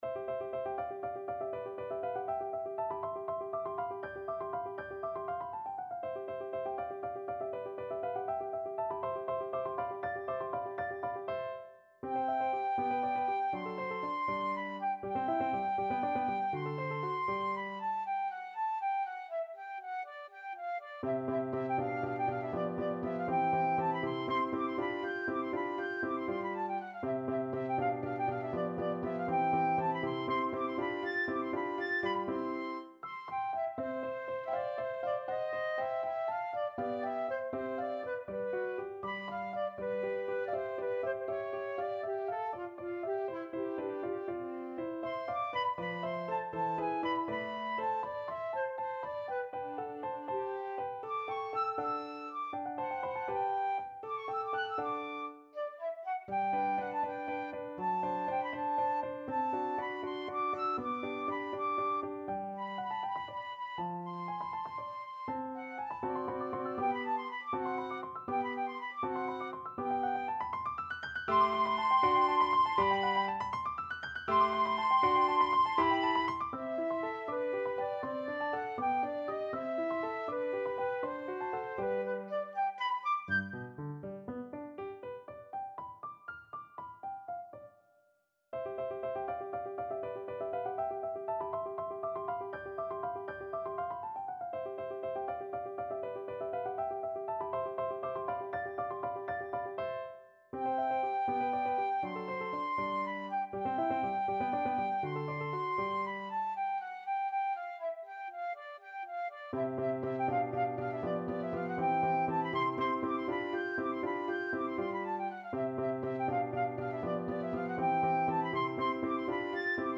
~ = 100 Allegro (View more music marked Allegro)
6/8 (View more 6/8 Music)
Flute  (View more Advanced Flute Music)
Classical (View more Classical Flute Music)